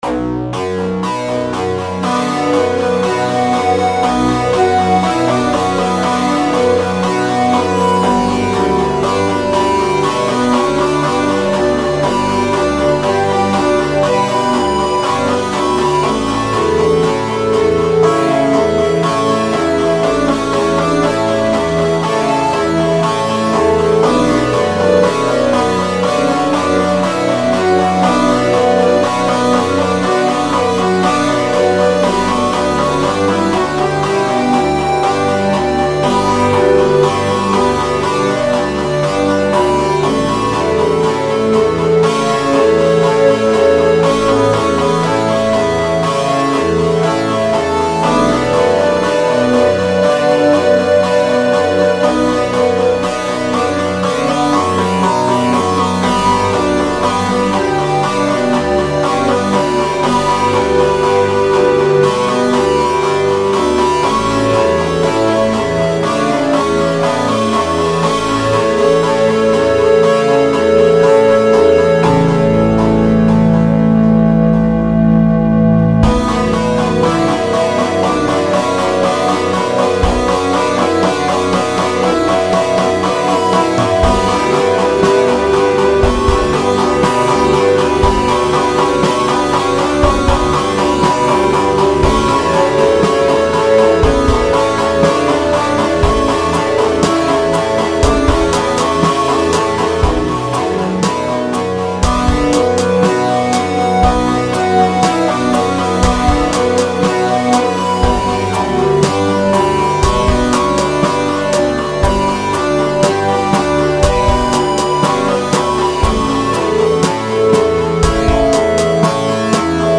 This version was made in Fruity Loops.